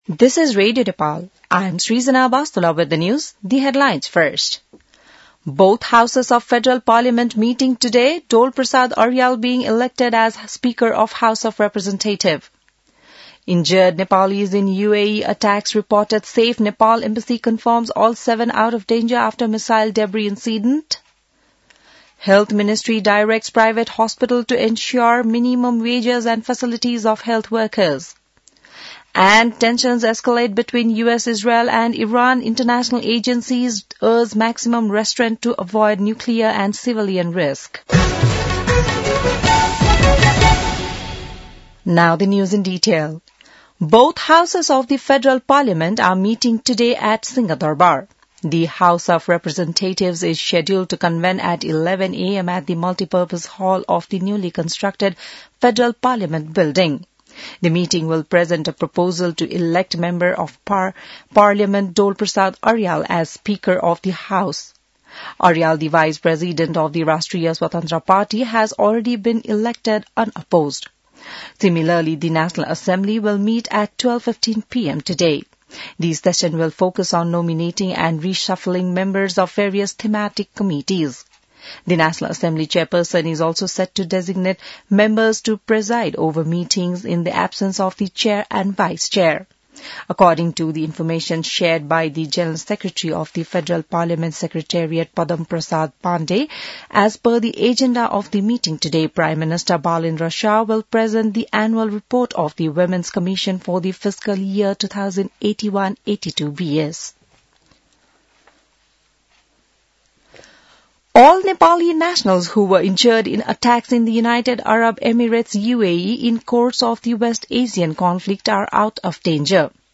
बिहान ८ बजेको अङ्ग्रेजी समाचार : २२ चैत , २०८२